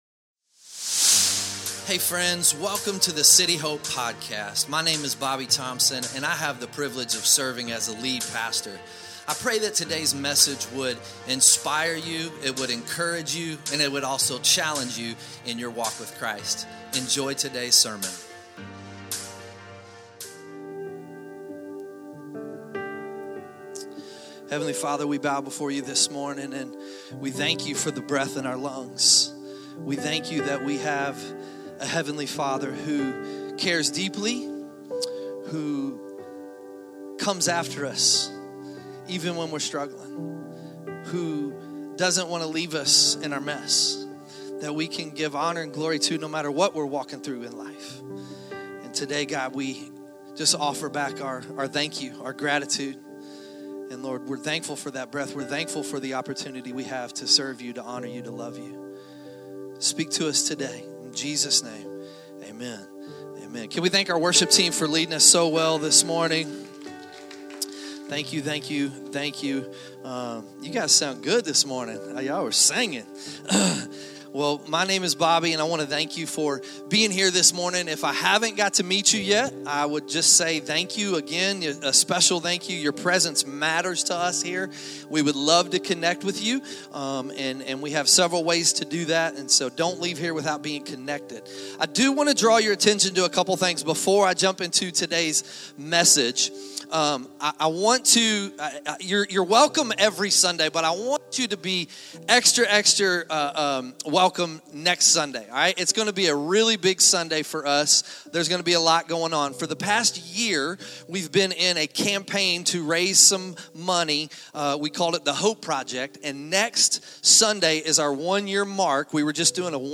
2025 Sunday Morning We started this chapter talking about the big implications of being united with Christ.